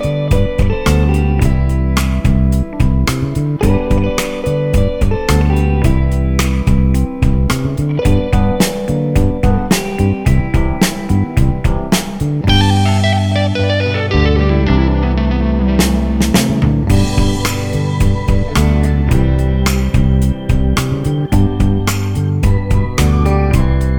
no Backing Vocals Rock 'n' Roll 3:33 Buy £1.50